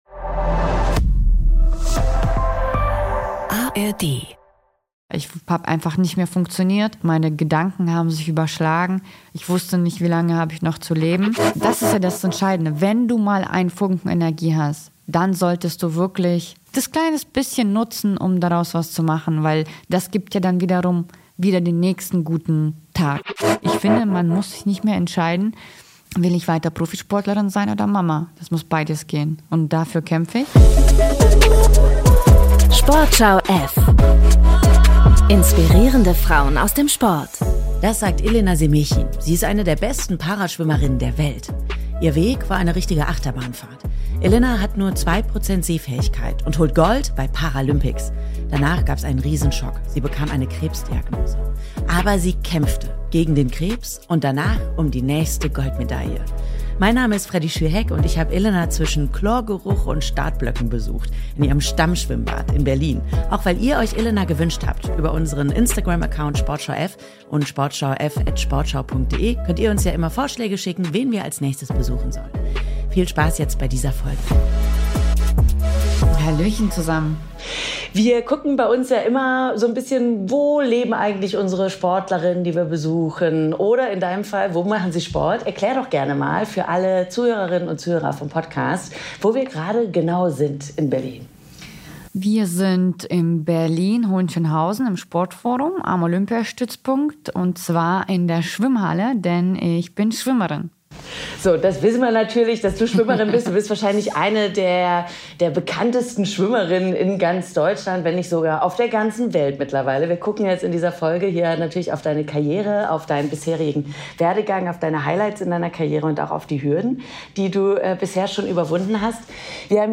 Im Gespräch mit Freddie Schürheck spricht sie über ihre Goldmedaillen und das Gefühl, Mutter zu werden. Es geht um Verantwortung, Sichtbarkeit und strukturelle Hürden im Leistungssport.